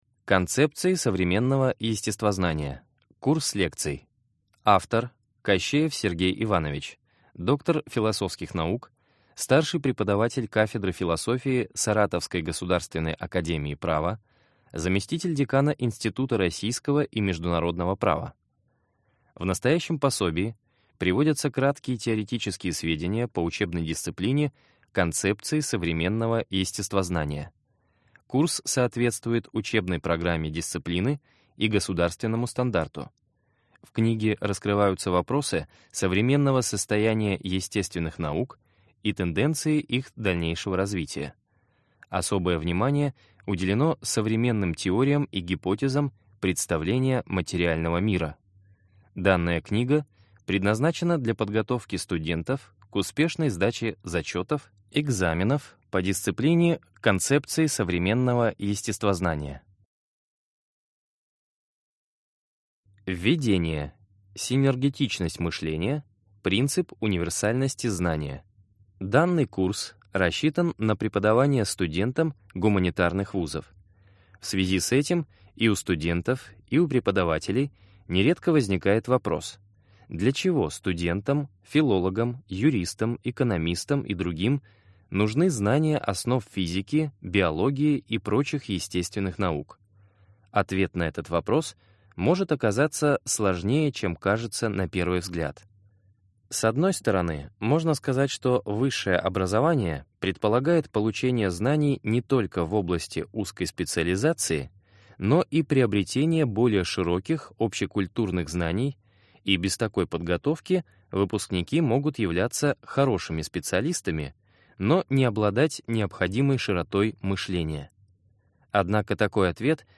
Аудиокнига Концепции современного естествознания. Курс лекций | Библиотека аудиокниг